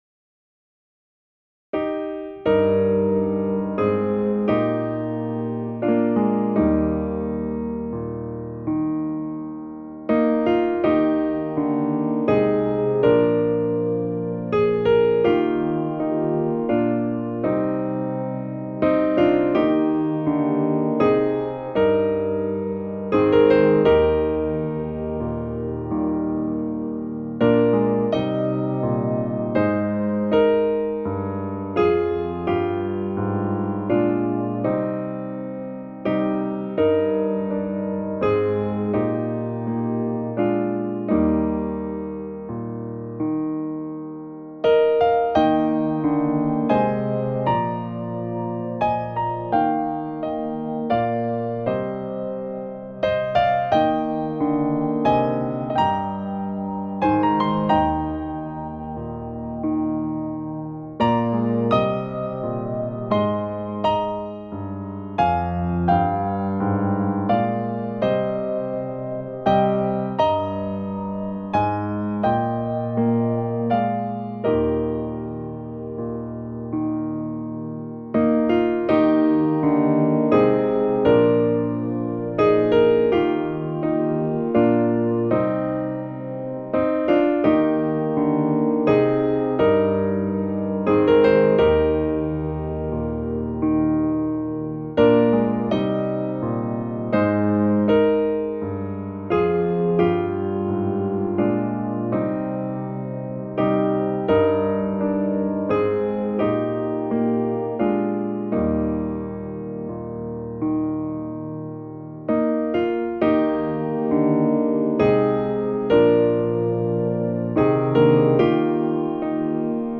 Music: Ancient Irish Melody
This entry was posted on Wednesday, May 5th, 2010 at 7:51 pm and is filed under hymns.